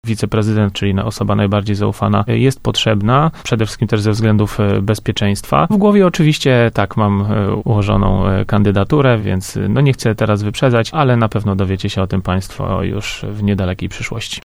O tym, że już niebawem w Tarnobrzegu zostanie powołany wiceprezydent mówił w piątek na antenie Radia Leliwa prezydent Tarnobrzega Łukasz Nowak.